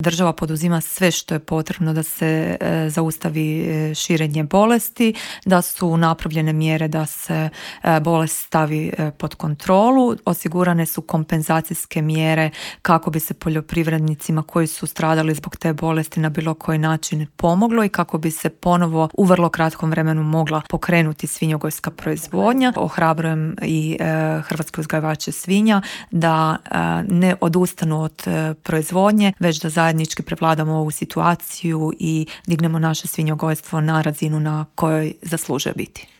U popodnevnim satima sastaje se i saborski Odbor za poljoprivredu čija je predsjednica Marijana Petir gostovala u Intervjuu Media servisa: